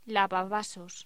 Locución: Lavavasos
voz